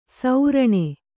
♪ sauraṇe